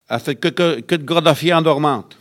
Enquête Arexcpo en Vendée
Collectif patois et dariolage
Catégorie Locution